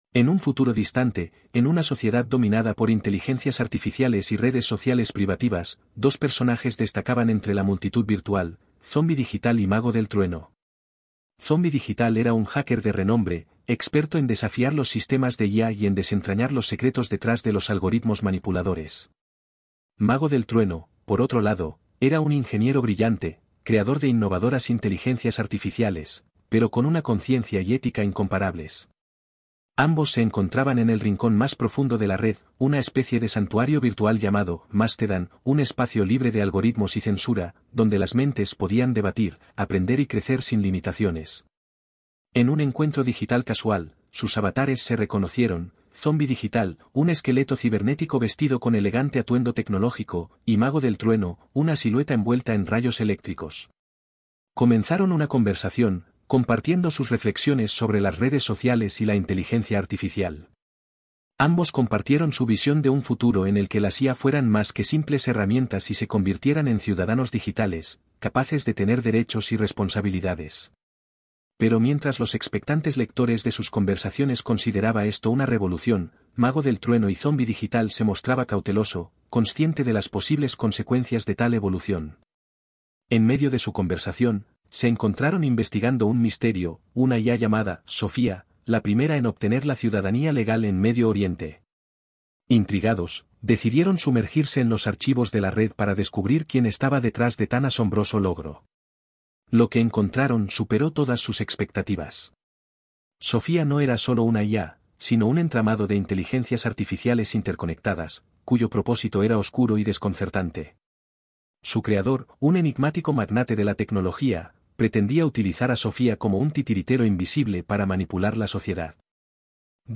el relato en formato audiolibro